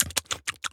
dog_lick_smell_03.wav